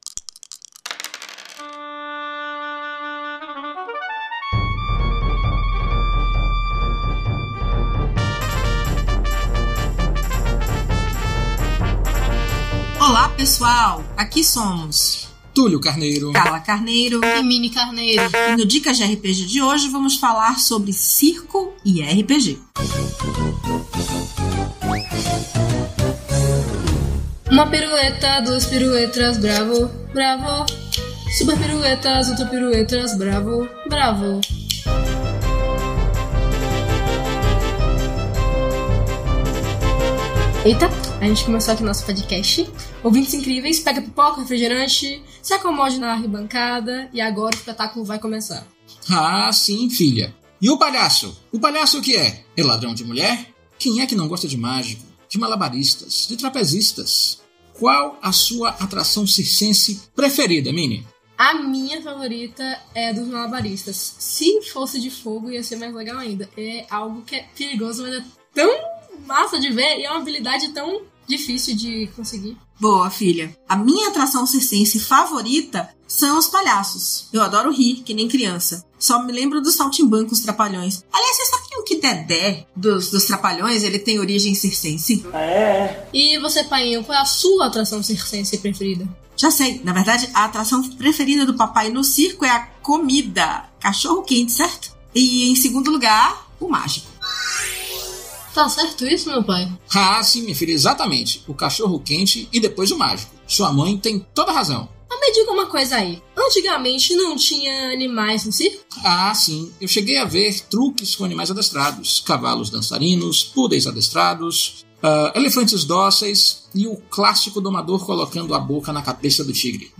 Músicas: Music by from Pixabay